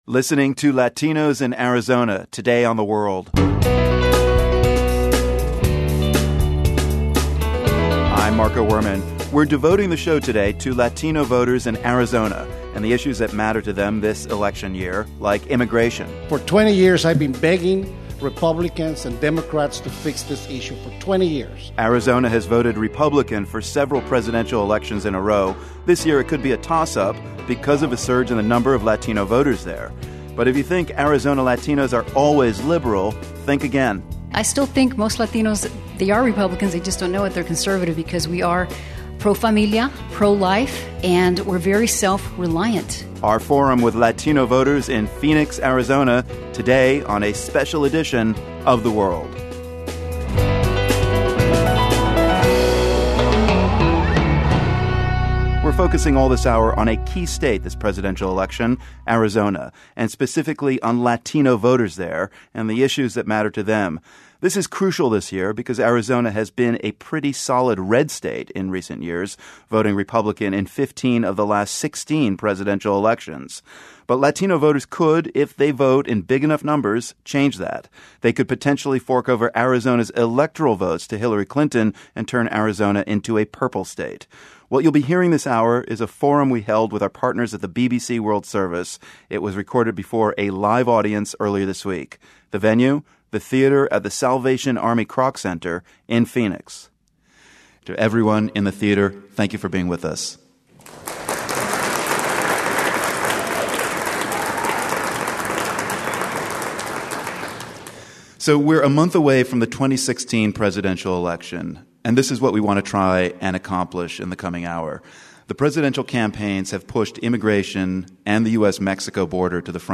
We assembled Latino political activists from the left and the right before an audience in Phoenix earlier this week, where Marco Werman moderated a lively discussion of topics including immigration, education and jobs.
This episode features an edited version of the conversation.